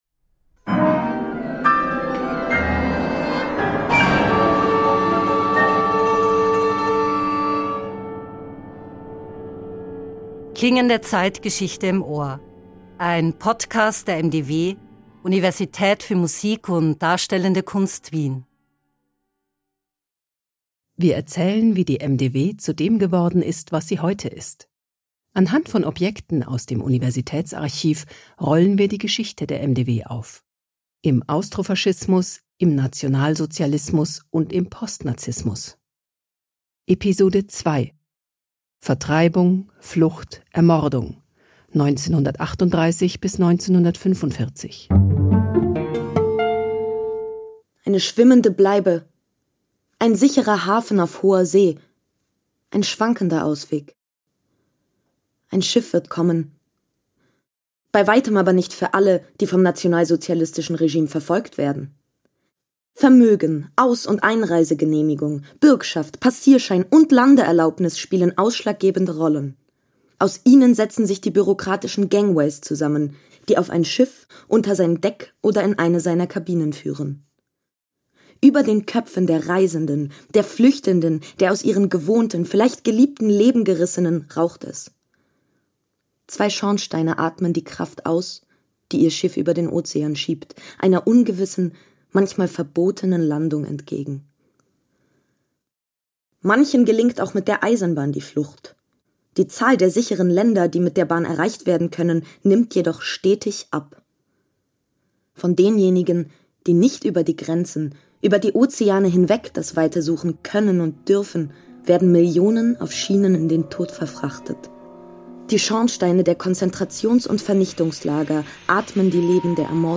Jede Folge verschränkt Collagen aus Originaldokumenten, die als szenischer Text arrangiert und eingelesen werden, mit zeitgenössischen Originalkompositionen und Sounddesign. Die unterschiedlichen Tonlagen aus Vergangenheit und Gegenwart werden von Komponist:innen weiterentwickelt, von Schauspiel-Studierenden werden sie zur Sprache gebracht.